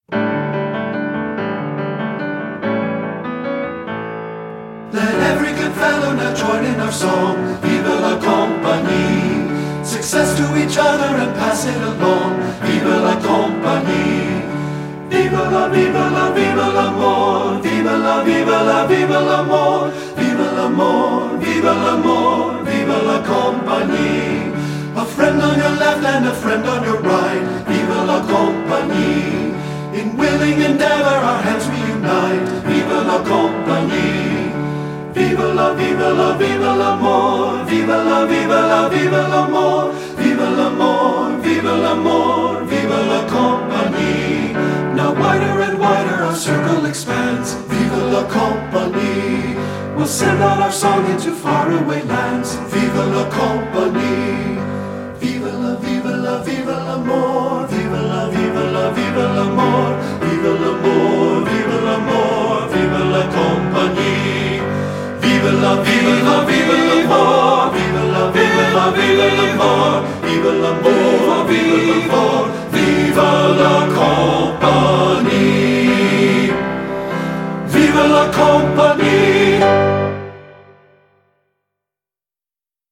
Voicing: TTB and Piano